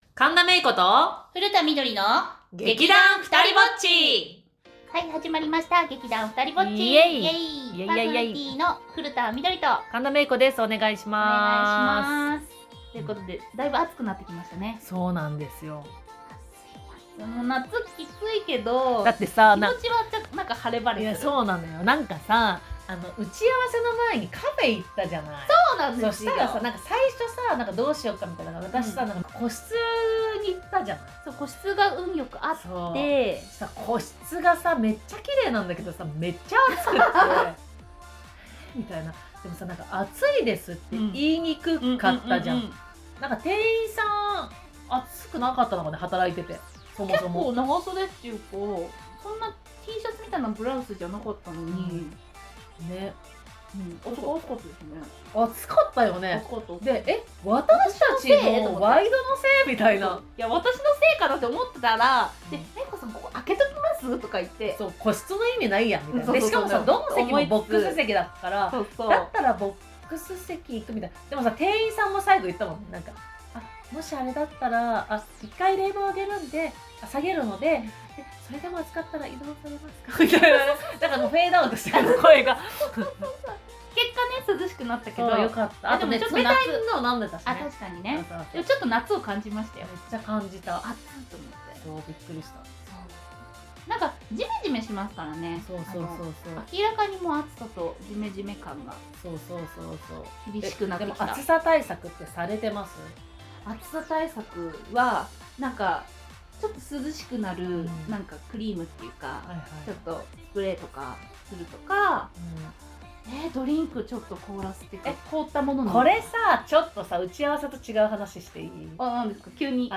劇団ふたりぼっちの ２人は夏の気になるあの話をしました☆ さぁ今回はどんなトークを繰り広げたか？